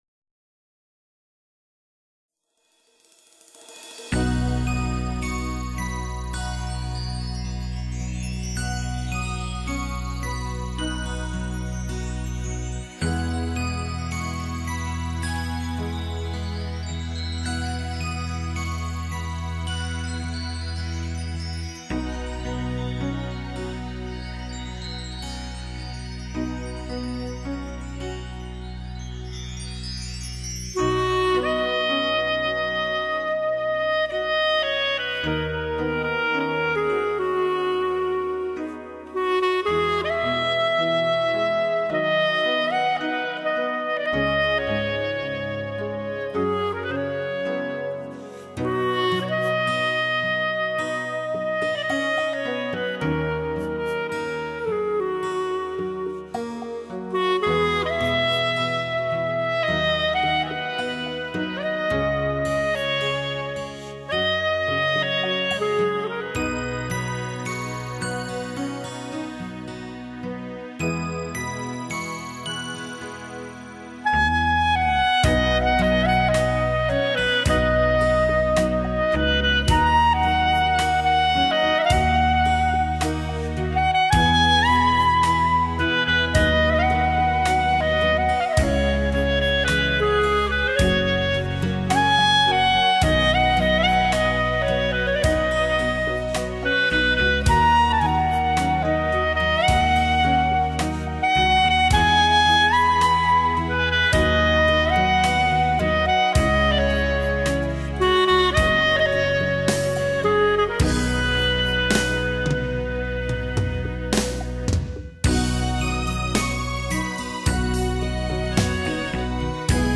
单簧管独奏
萨克斯独奏
吉它伴奏
马头琴伴奏
小提琴伴奏
双簧管伴奏
长笛伴奏
小号伴奏
二胡伴奏
琵琶伴奏
古筝伴奏